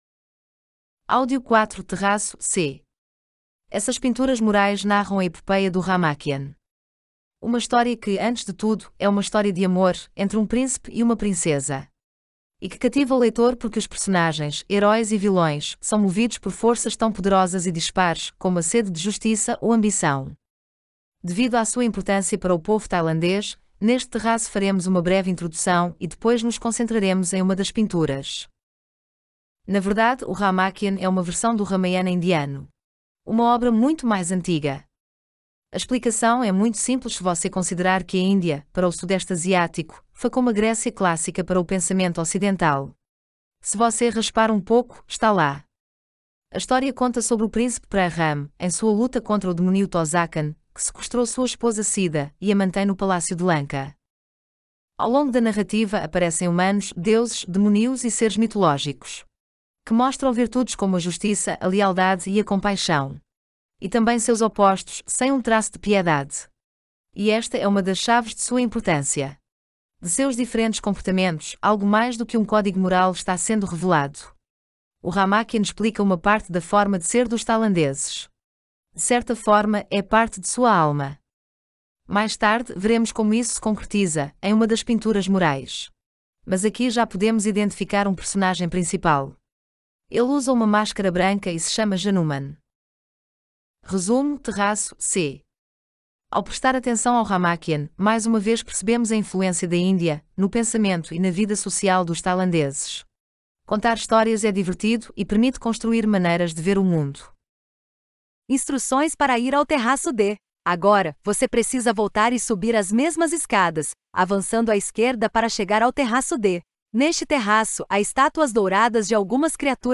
Como são os audioguias?